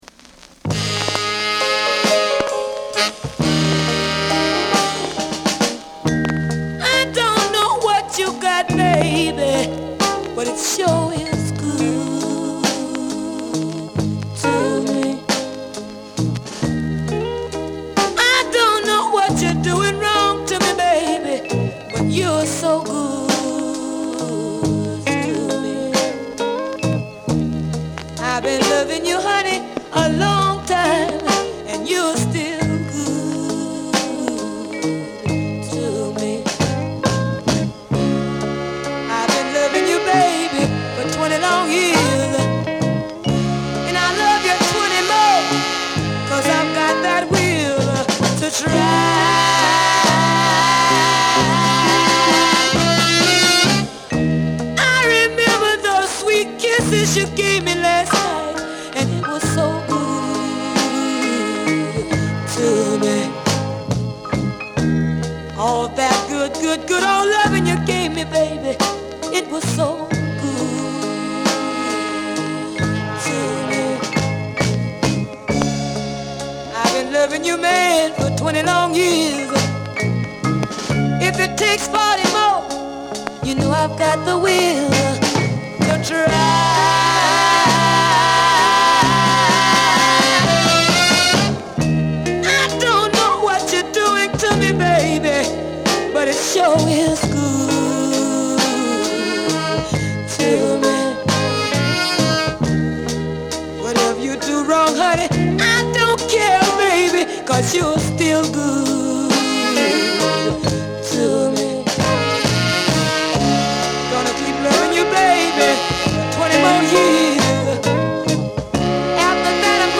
バラード、R&B共に流石のヴォーカル！